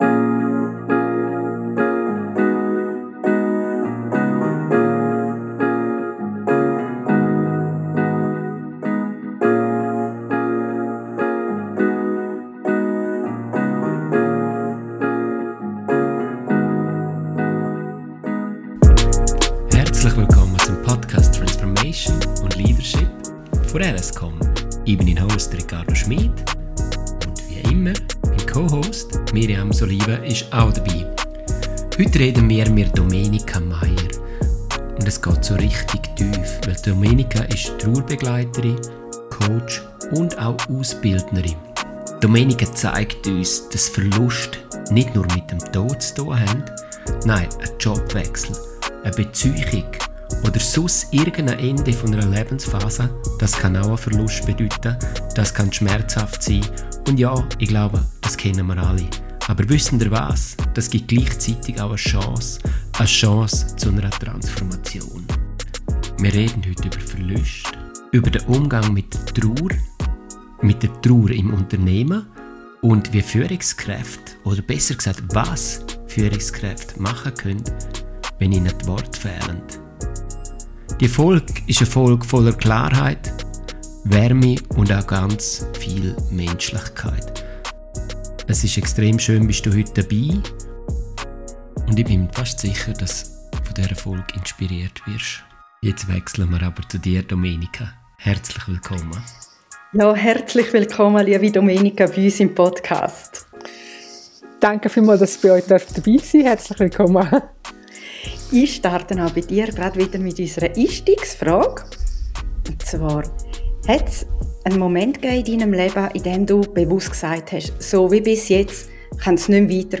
Klarheit und Mut (Schweizerdeutsch)
Ein Gespräch.